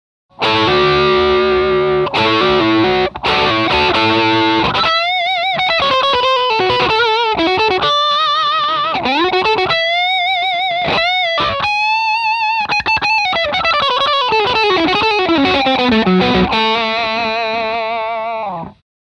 Two different Distortion Blender - Bold Distortion
This effect has the mixed outputs of bold tone. Both inside Crunch Distortion and Heavy Distortion are mixed half-and-half.
Demo with Single Pickup 1